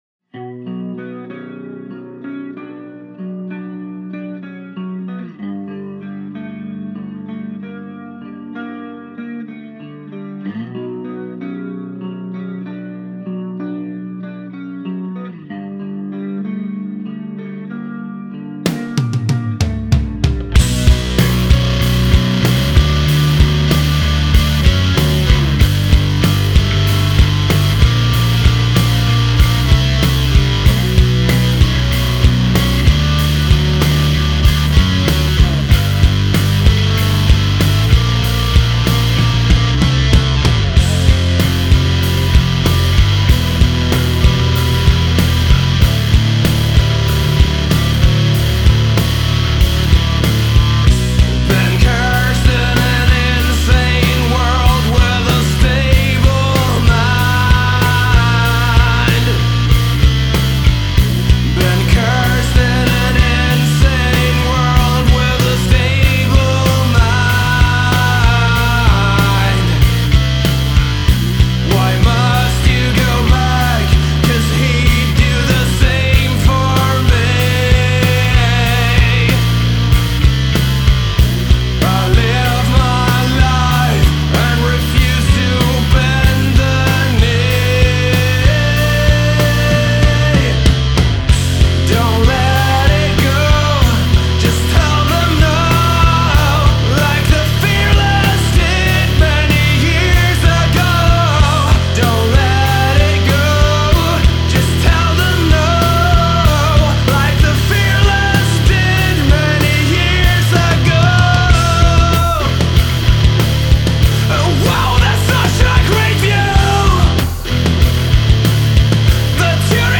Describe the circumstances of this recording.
In the studio - August 10, 2020